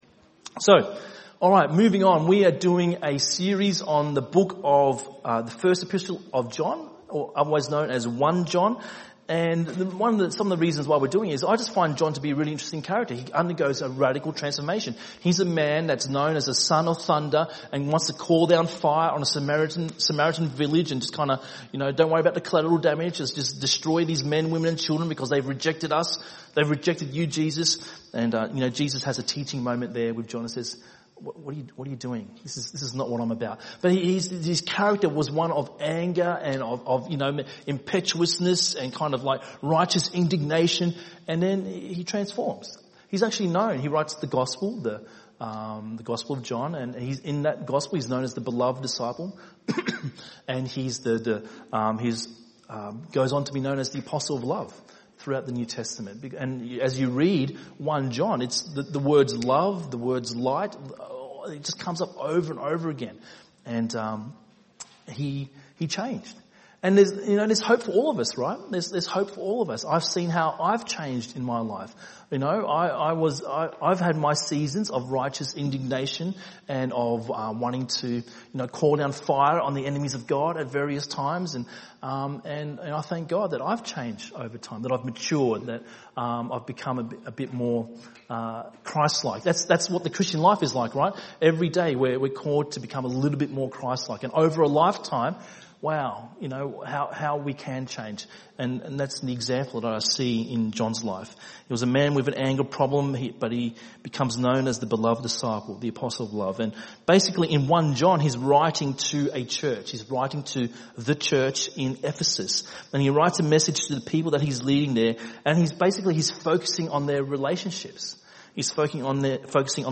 by enmelbourne | Nov 11, 2019 | ENM Sermon